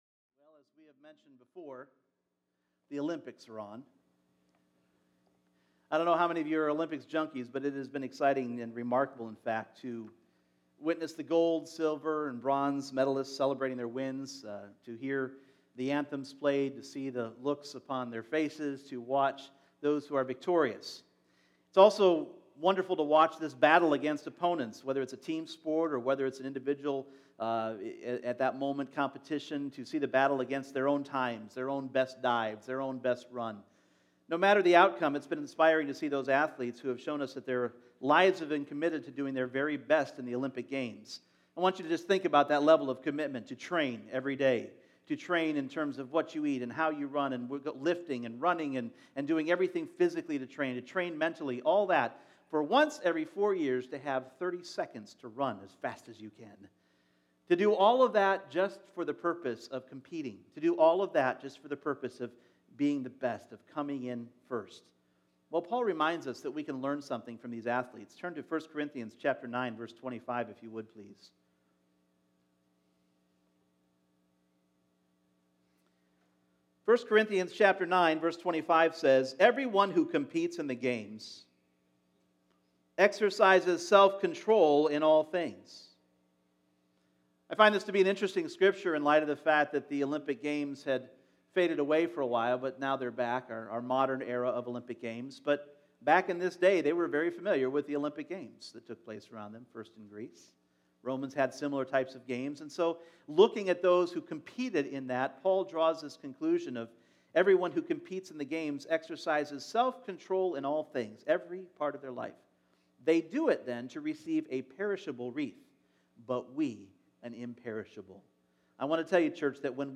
Purpose Service Type: Sunday Morning Picture your purpose in life as one city.